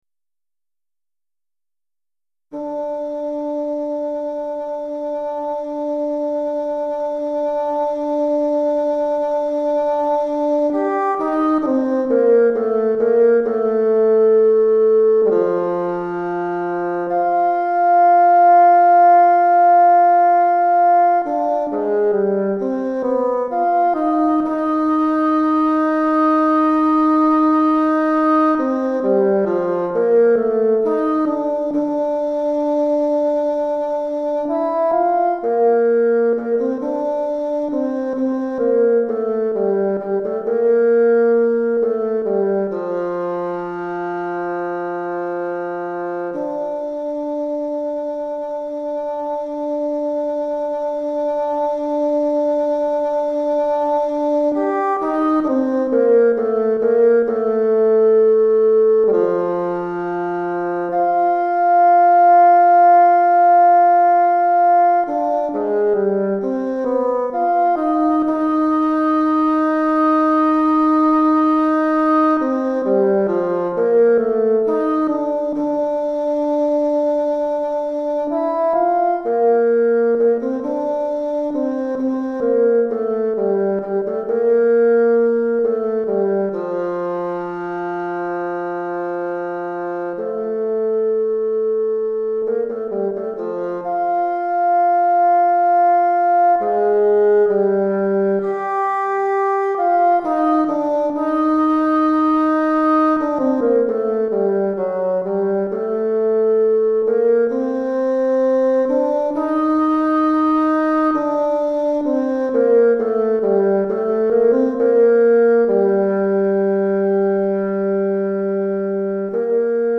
Basson Solo